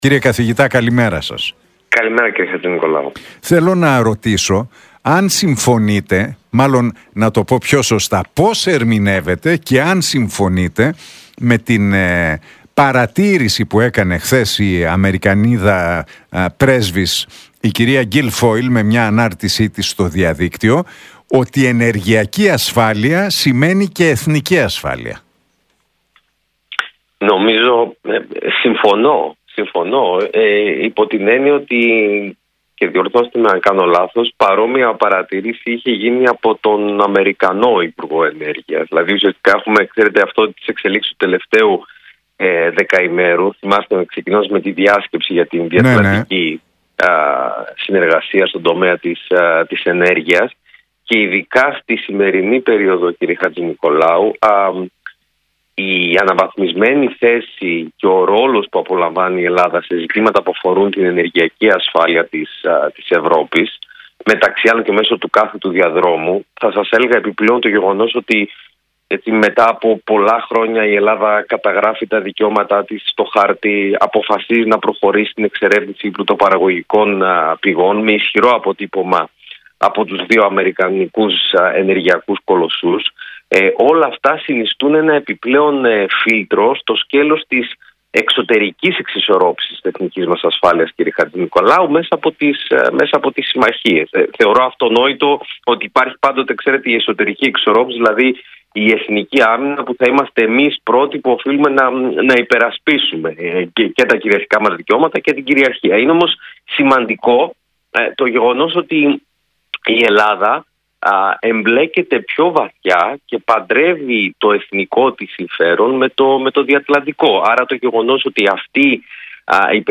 στον Realfm 97,8 για την ενεργειακή συμφωνία Ελλάδας – Ουκρανίας